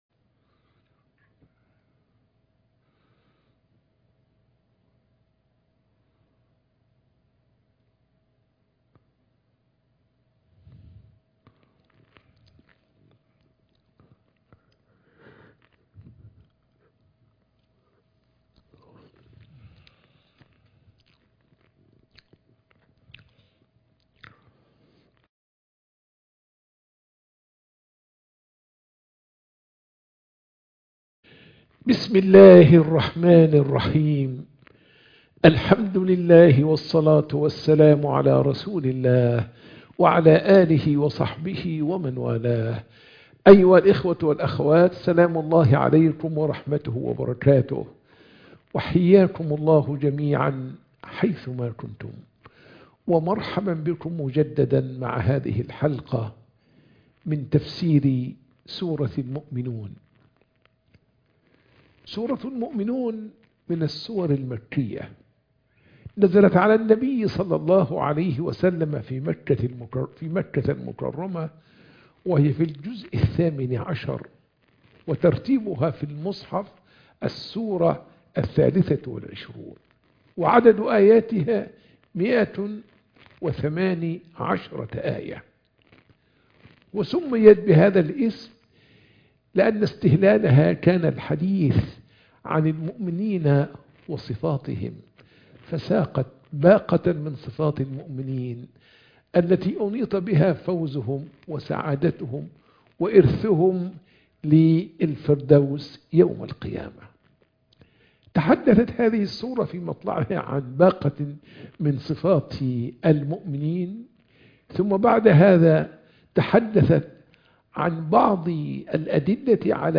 محاضرة التفسير - سورة المؤمنون